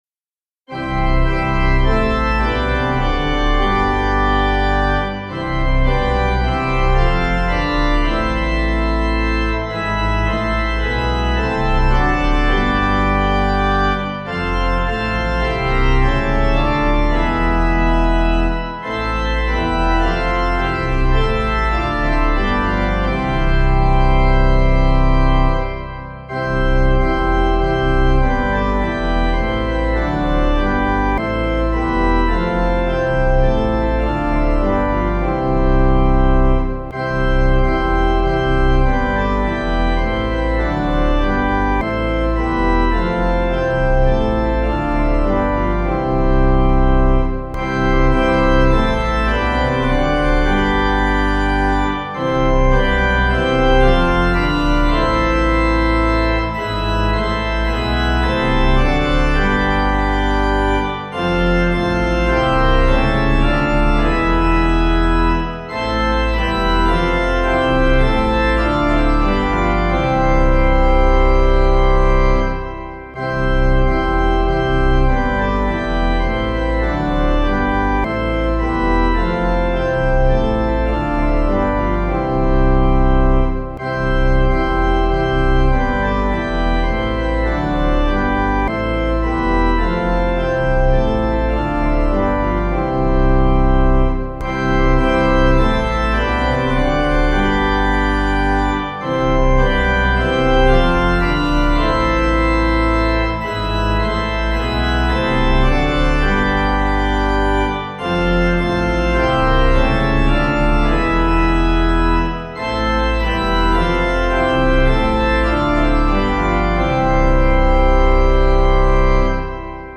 Practice singing the hymns for this Sunday’s worship services using the sheet music and audio accompaniment below.